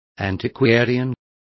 Complete with pronunciation of the translation of antiquarians.